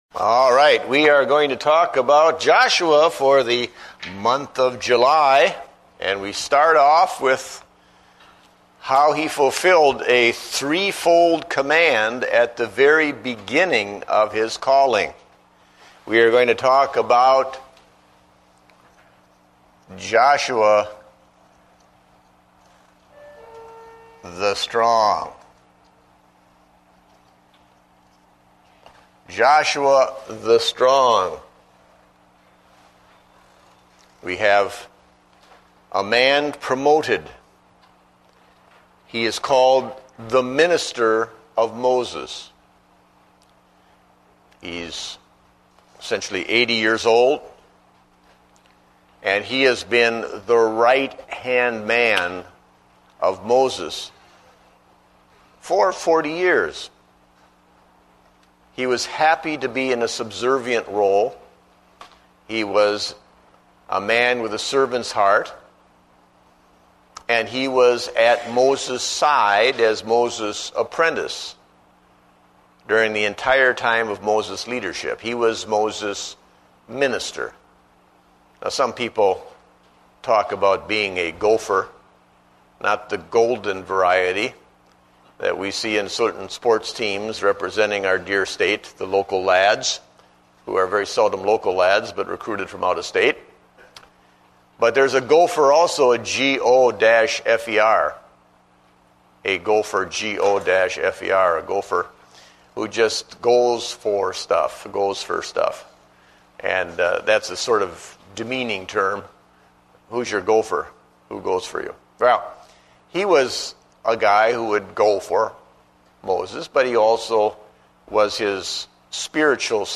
Date: July 5, 2009 (Adult Sunday School)